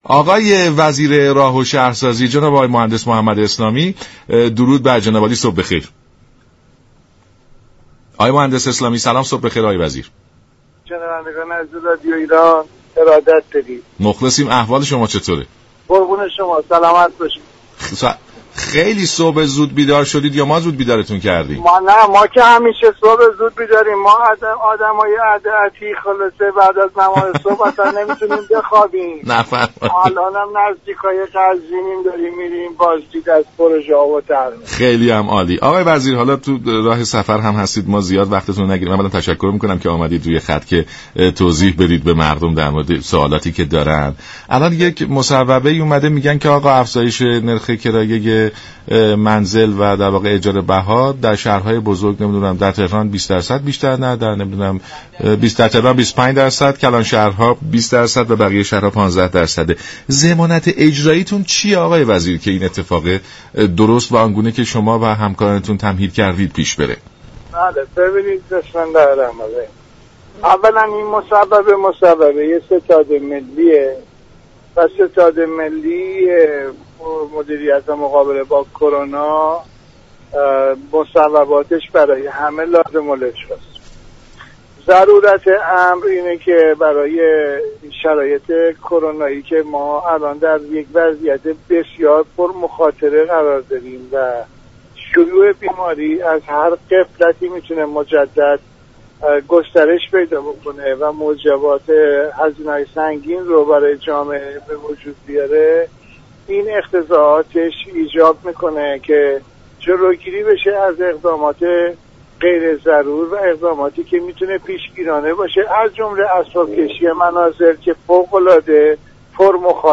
وزیر راه و شهرسازی در برنامه سلام صبح بخیر رادیو ایران گفت: ستاد ملی مبارزه با كرونا در راستای كاهش التهاب های روانی موجود در بازار و آسیب های ناشی از اسباب كشی خانه، دستور تمدید اجاره نامه های مسكن را داده است.